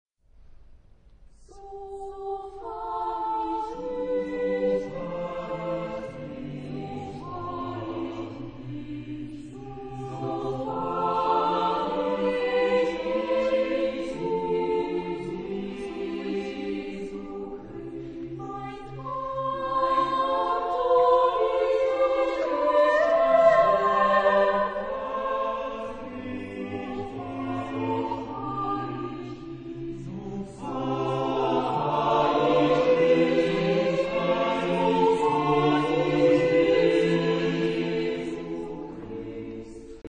Genre-Style-Form: Sacred ; Baroque ; Motet
Type of Choir: SSATB  (5 mixed voices )
Tonality: D major
sung by Junger Kammerchor Rhein Neckar
Discographic ref. : 7. Deutscher Chorwettbewerb 2006 Kiel